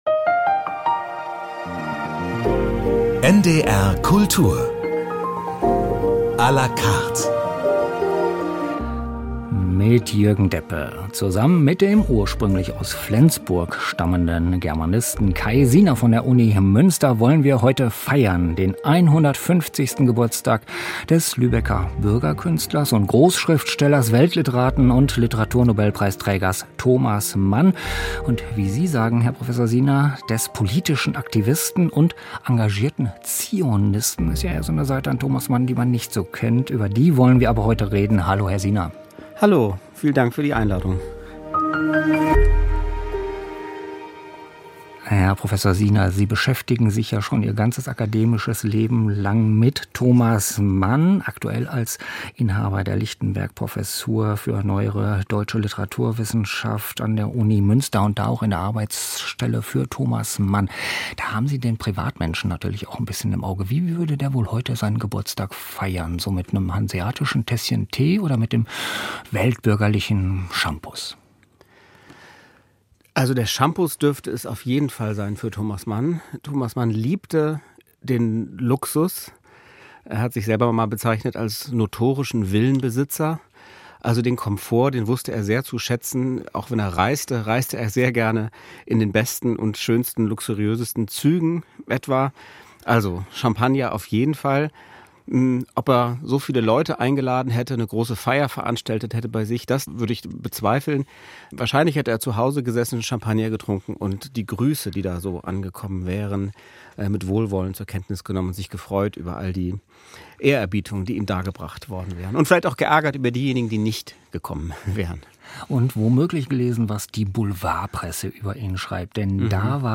Choralvorspiel für Orgel, BWV 659 (arr.)